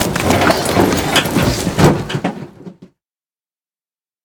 Ledge Climbing Sounds Redone